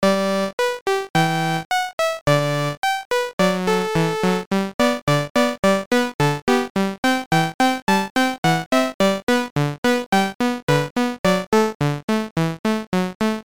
home *** CD-ROM | disk | FTP | other *** search / Aminet 19 / Aminet 19 (1997)(GTI - Schatztruhe)[!][Jun 1997].iso / Aminet / mods / chip / THXmuzza.lha / THXmuzza / THX.fROGGA! ( .mp3 ) < prev next > Abyss Highest Experience Module | 1997-03-01 | 644 b | 2 channels | 44,100 sample rate | 13 seconds Title fROGGA!